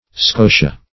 Scotia \Sco"ti*a\, n. [L.]